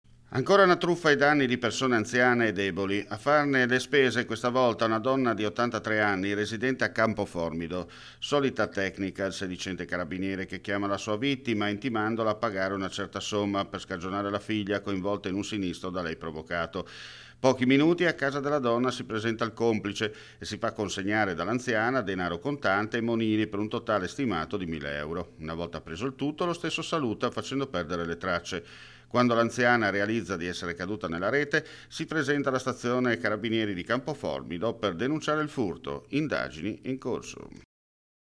FRIULTV GIORNALE RADIO: LE ULTIME NOTIZIE DAL FRIULI VENEZIA GIULIA